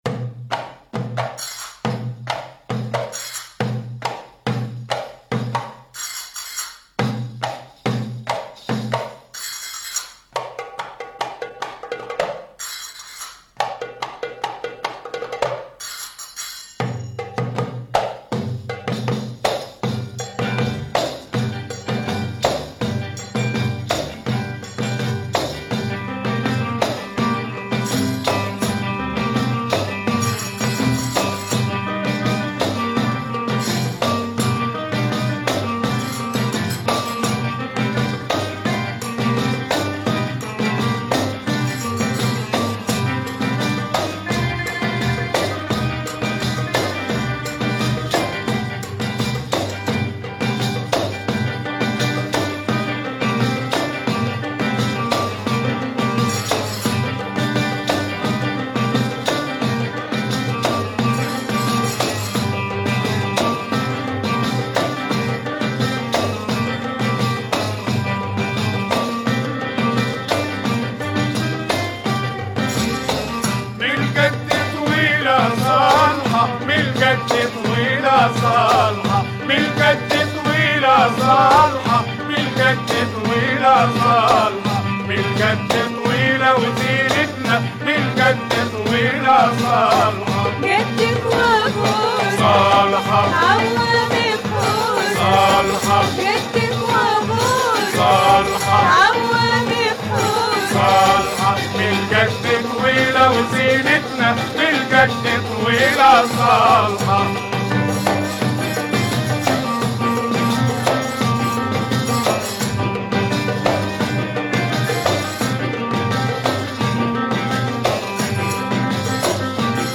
Super rare Egyptian album
two killer Arabic tunes